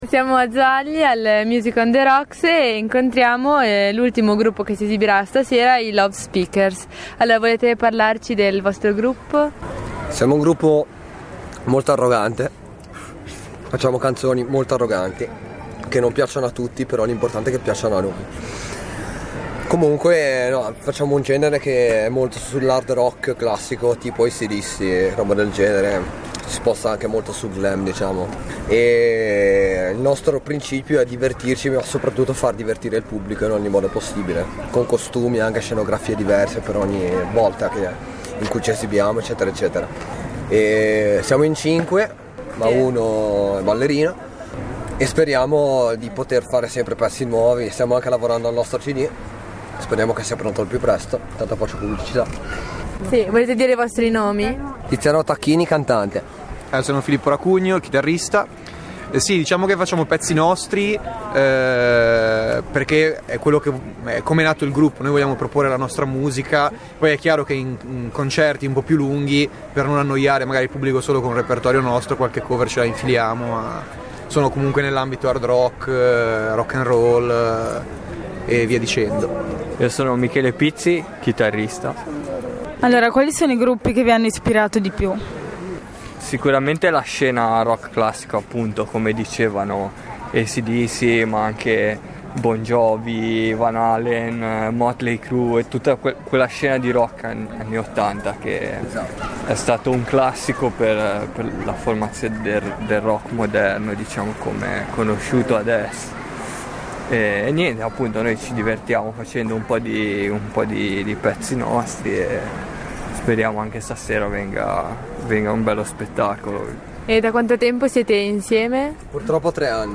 play_circle_filled Intervista a "Lovespeakers" Radioweb C.A.G. di Rapallo Gruppo musicale intervista del 29/08/2013 Intervista al gruppo rock Lovespeakers attivo soprattutto nella zona del Tigullio. Il gruppo, che attinge al glam-rock degli anni ottanta, presenta brani originali ed uno spettacolo live divertente e coinvolgente suonando spesso nelle rassegne locali per i gruppi emergent e nei locali dela zona.